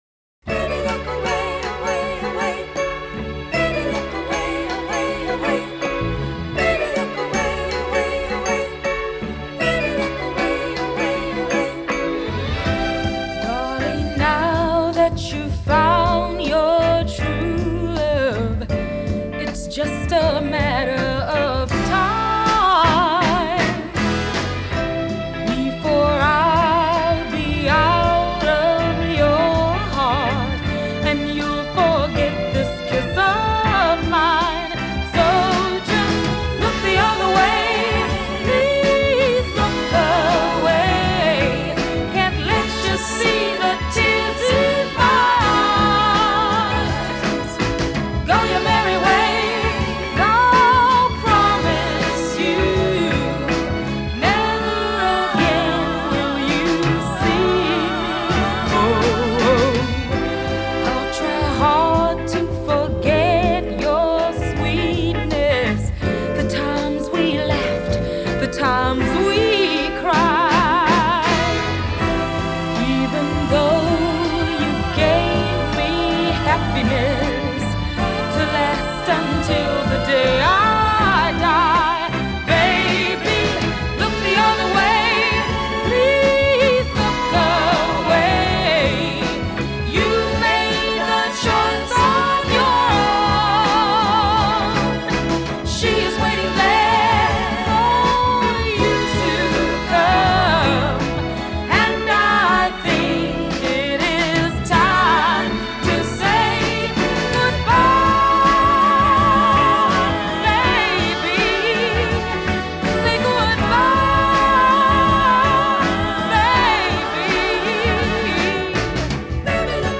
Genre: Funk / Soul